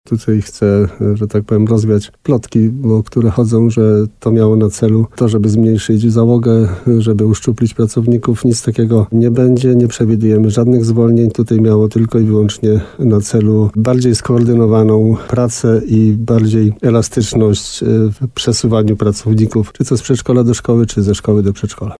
– Zgodzili się już na to radni – mówi Jan Kotarba, wójt gminy Rytro.